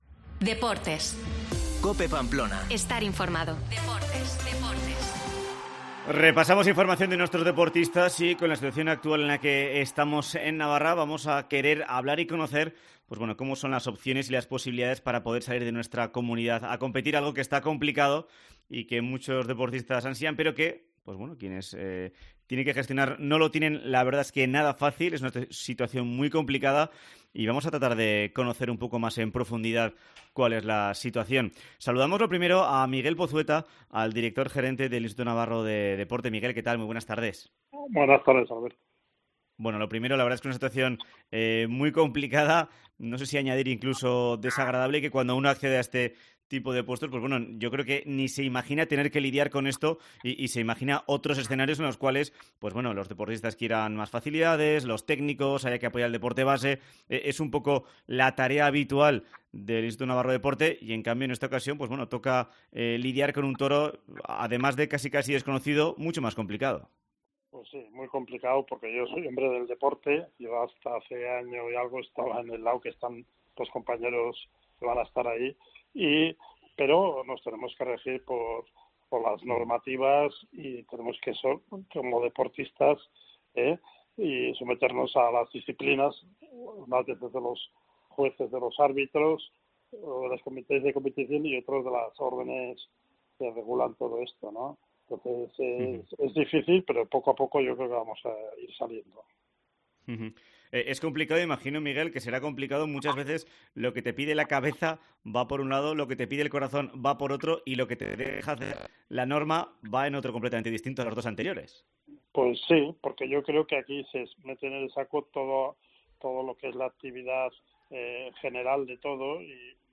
Programa en COPE Navarra sobre la movilidad de los deportistas.